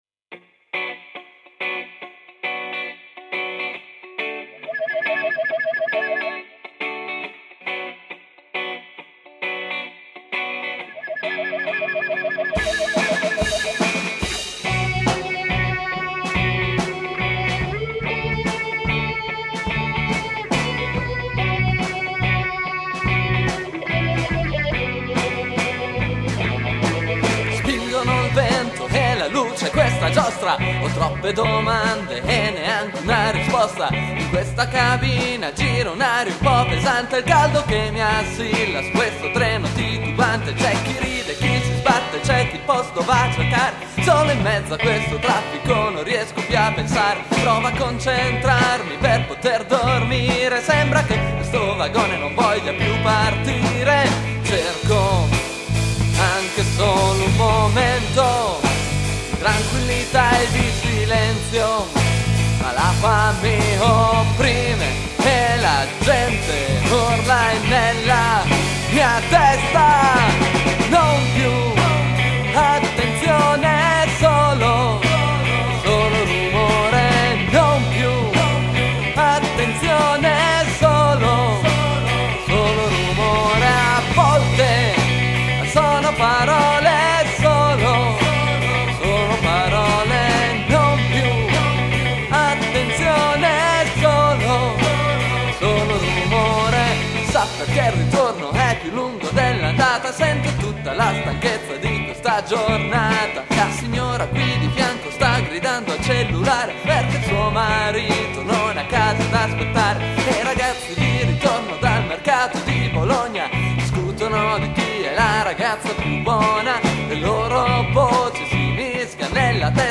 GenereRock